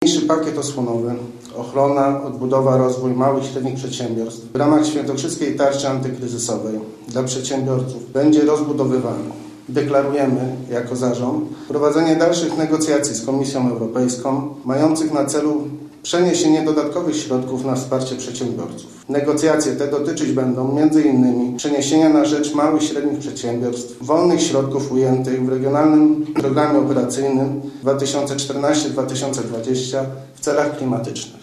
Mówi marszałek Andrzej Bętkowski.